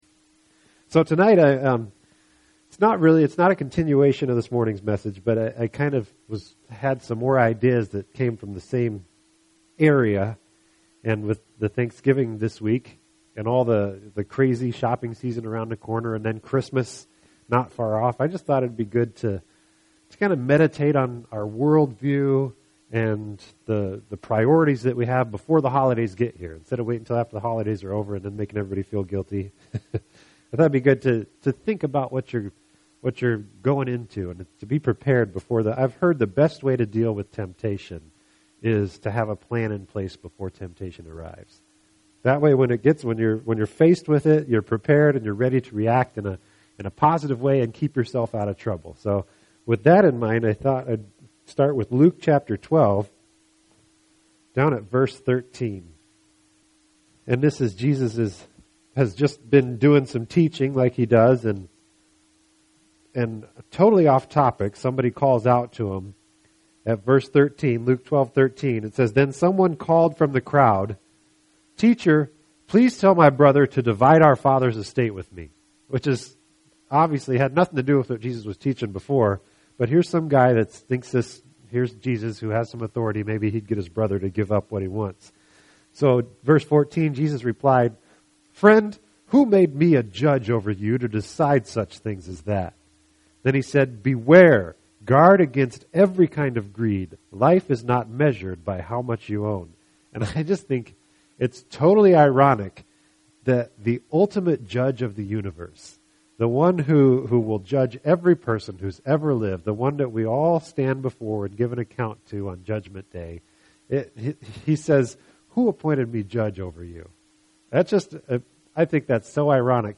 Podcast: Play in new window | Download (Duration: 43:04 — 19.7MB) This entry was posted on Thursday, November 1st, 2012 at 1:23 am and is filed under Sermons .